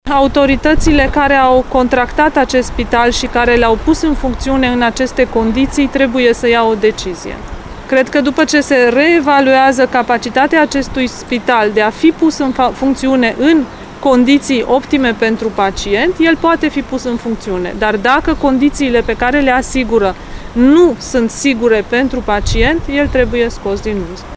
Aflat la Iași, ministrul Sănătății Ioana Mihăilă a răspuns întrebărilor Radio HIT referitoare la Spitalul mobil de la Lețcani.
Declarația ministrului Sănătății, Ioana Mihăilă